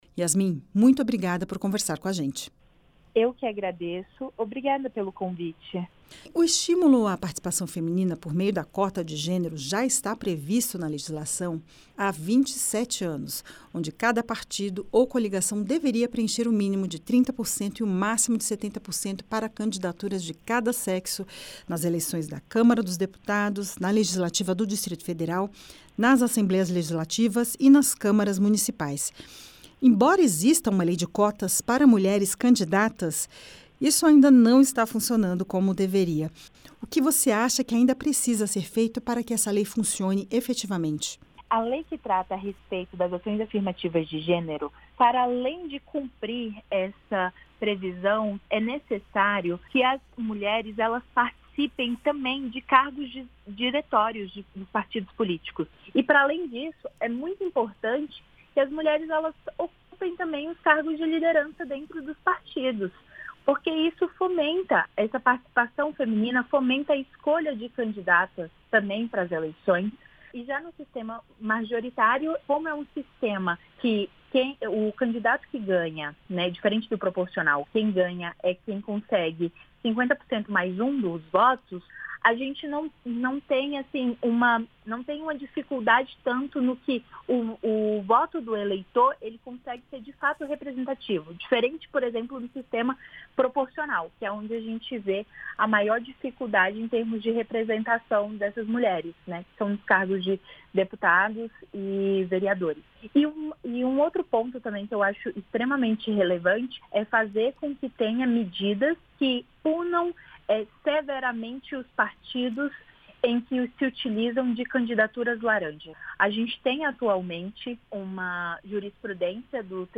Advogada fala sobre paridade de gênero na política e mecanismos para aumentar a participação feminina